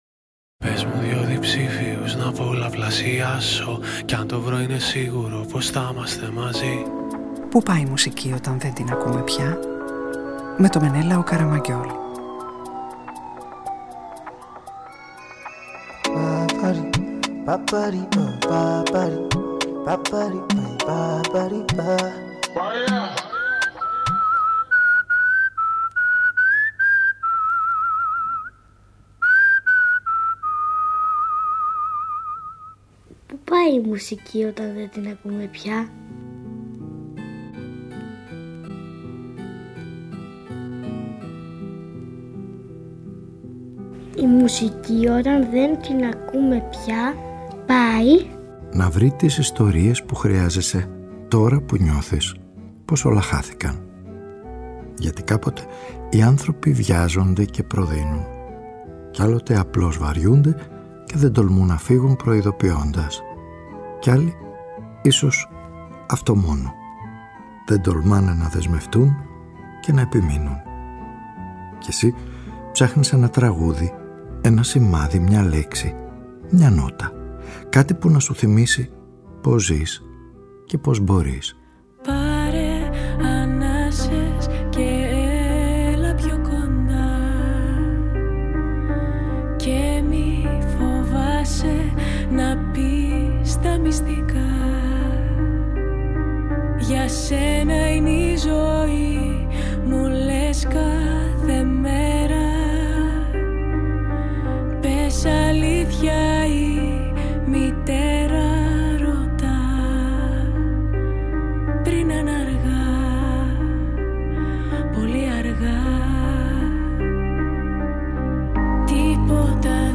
Ο ήρωας της σημερινής ραδιοφωνικής ταινίας ανιχνεύει την αναγκαιότητα και τη διάρκεια των δεσμεύσεων και την τήρηση των υποσχέσεων ενώ ανακαλύπτει πως ό,τι ψάχνει αλλού, μπορεί να το βρει πρώτα μπροστά του, ίσως και μέσα του.